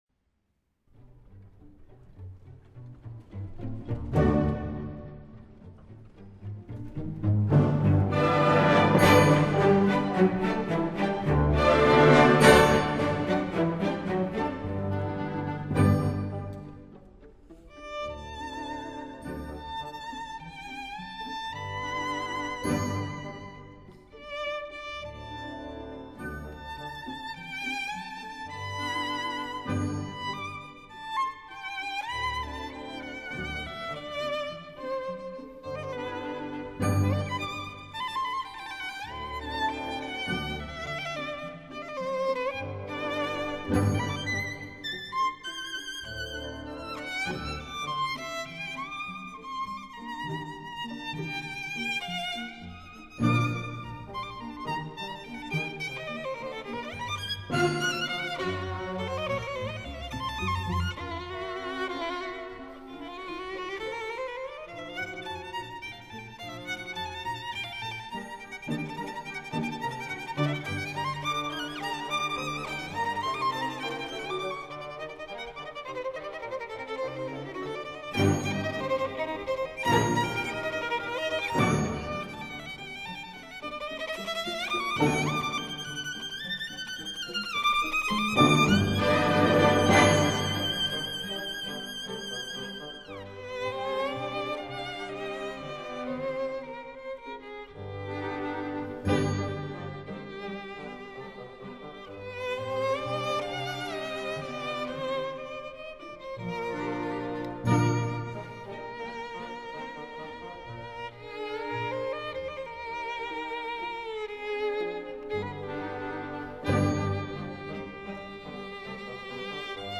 小提琴独奏